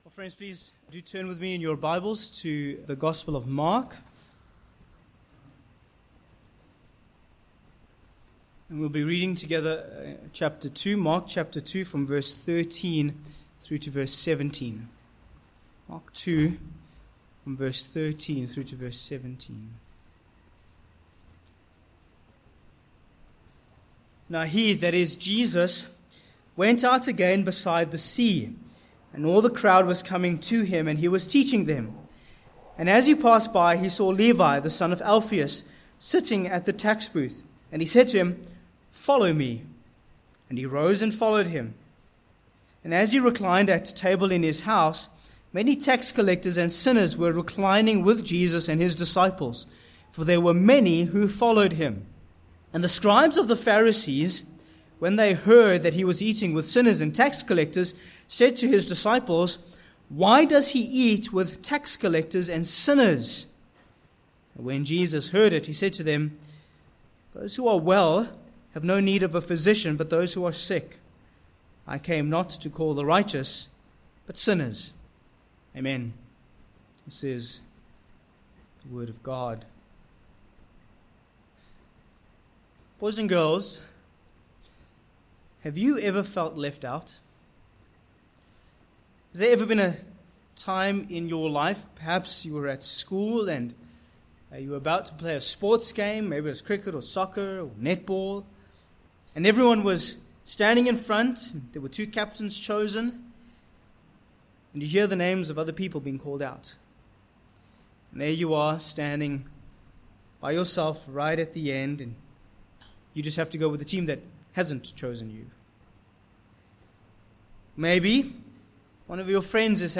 Passage: Mark 2:13-17  Sermon points: 1. Beside the Sea v13-14
Service Type: Morning